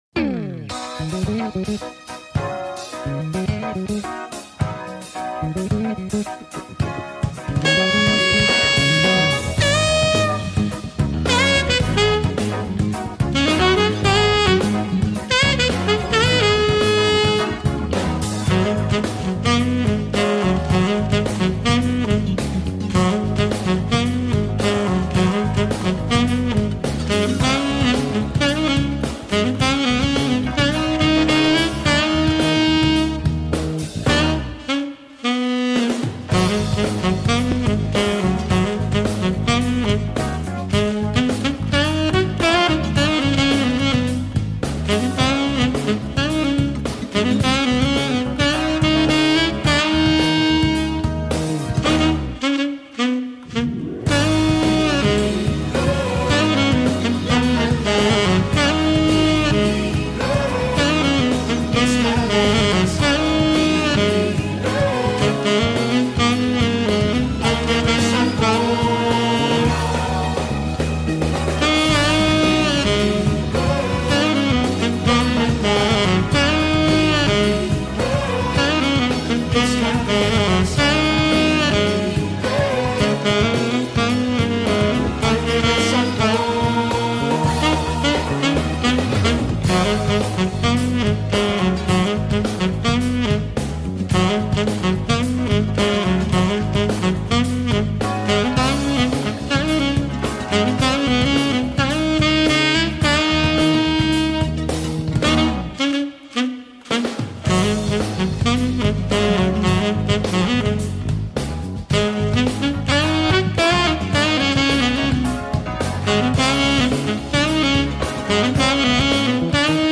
SAX - Latino